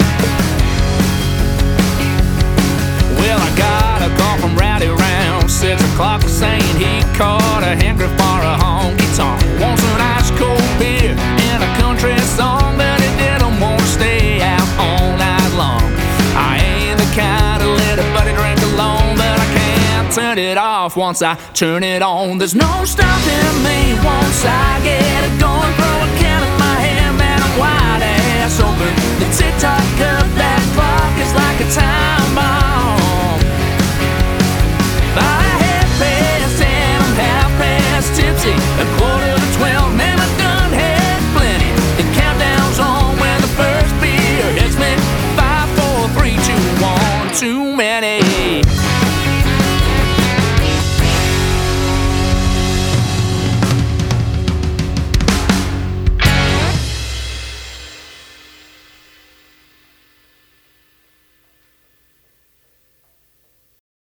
High Energy 4-Piece Country Party Band